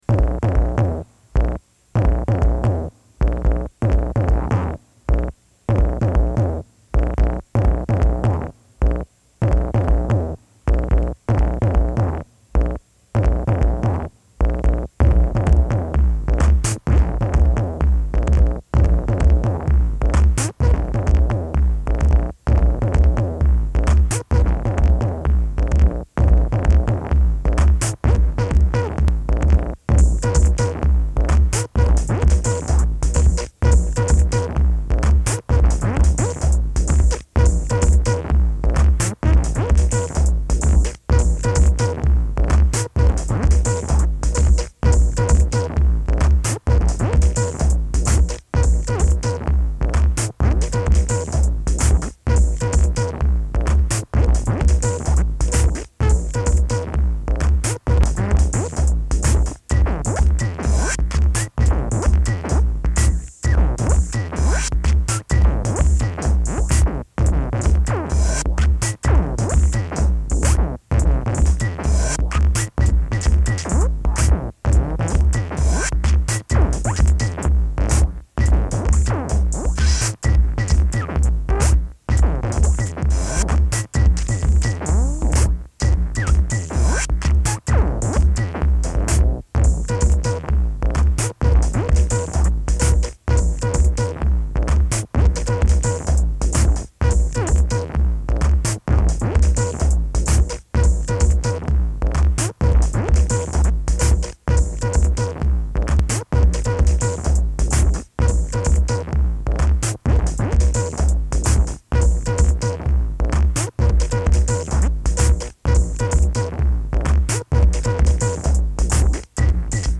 lo-fi analog techno and electro